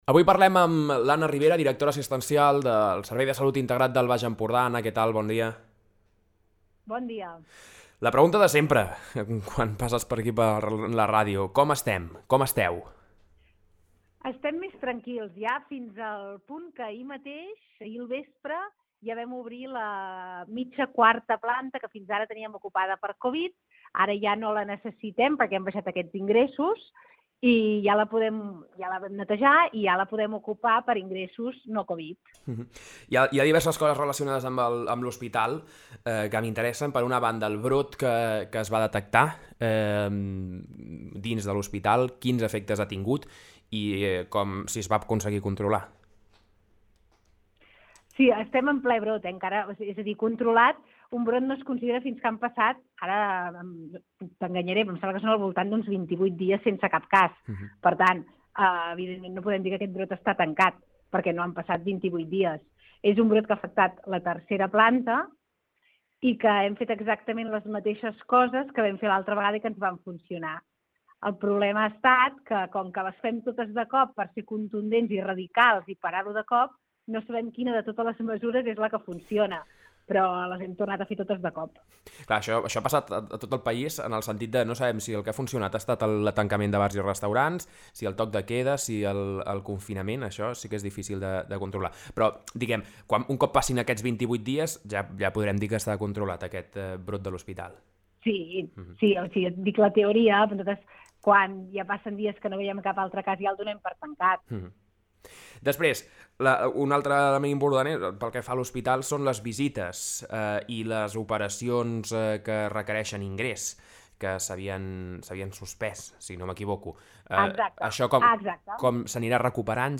Aquí teniu l’entrevista sencera